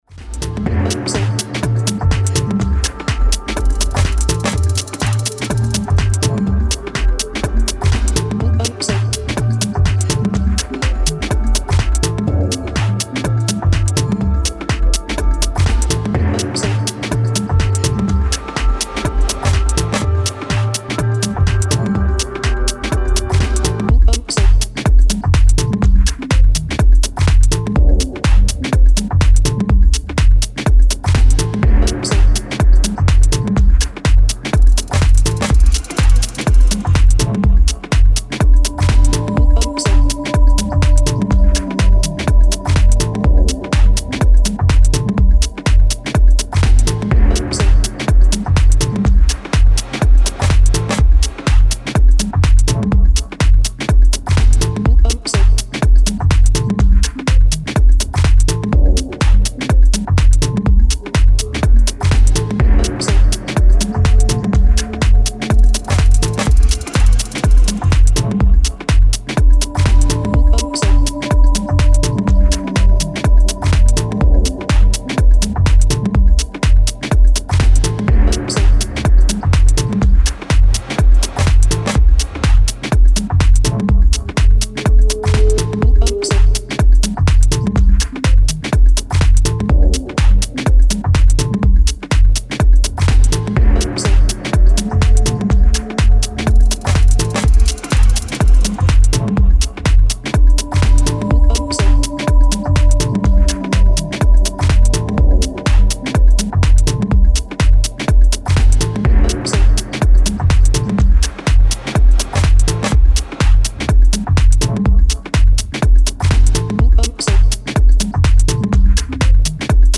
Style: Minimal Techno